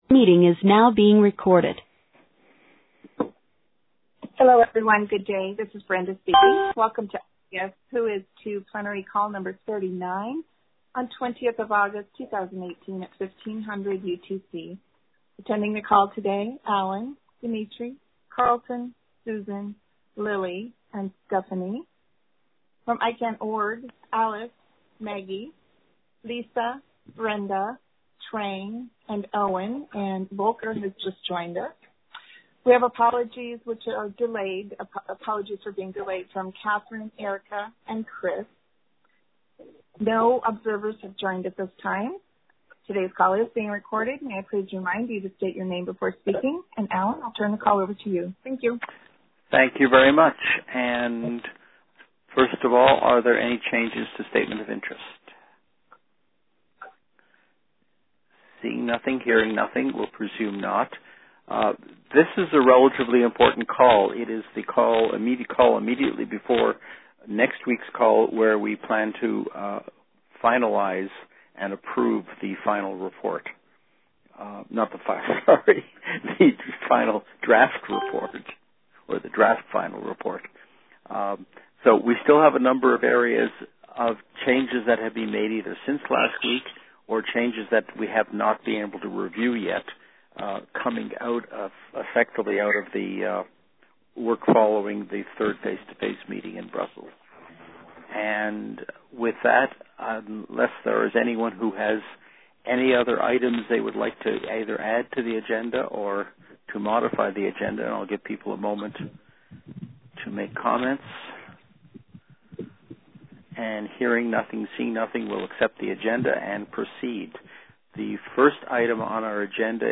rds-whois2-rt-plenary-20aug18-en.mp3